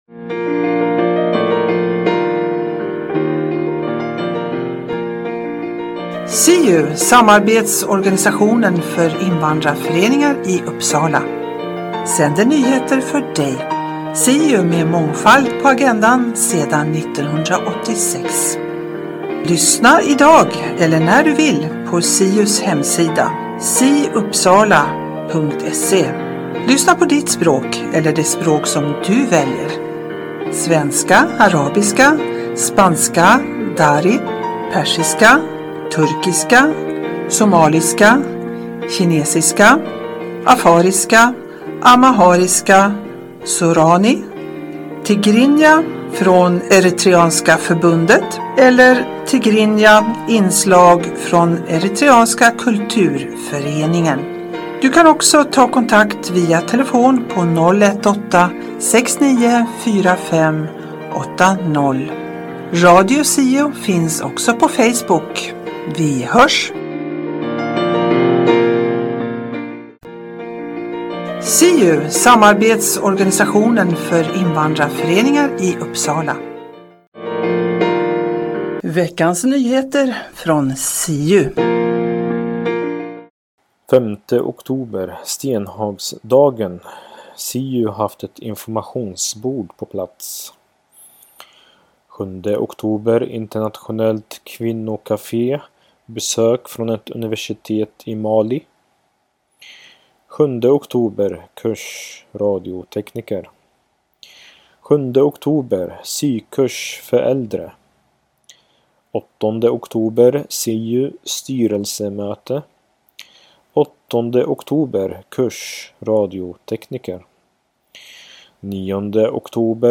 Siu-programmet på svenska innehåller SIU:s nyheter, Nyheter Uppsala och Riksnyheter. Berika din fritid med information och musik.